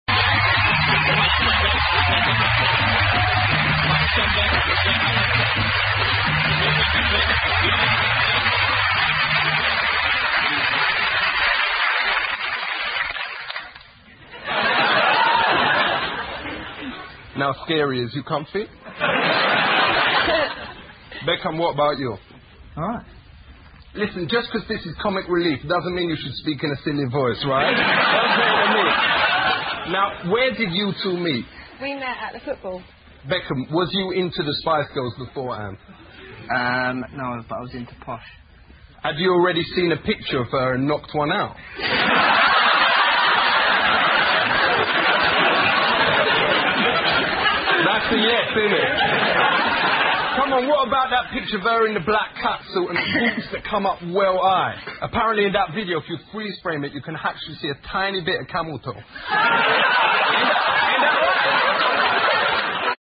西部落：贝克汉姆夫妇超搞笑访谈-1 听力文件下载—在线英语听力室